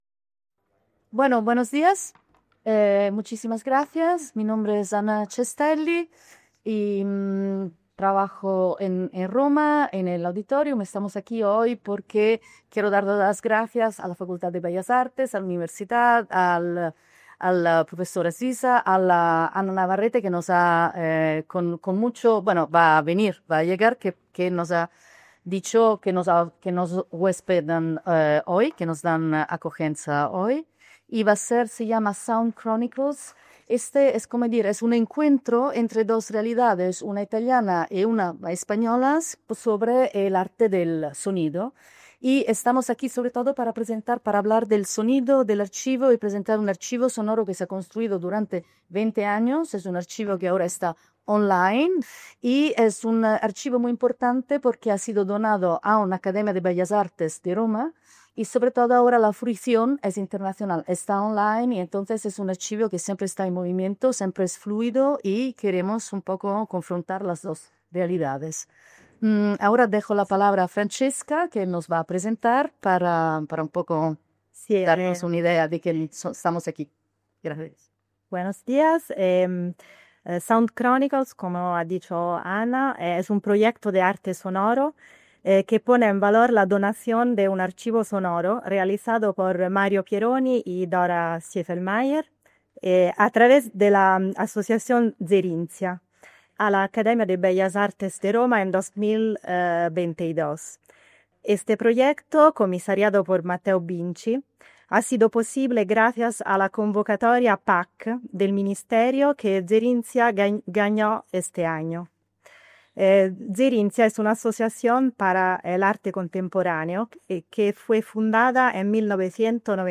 Pincha aquí para escuchar la jornada completa de ponencias.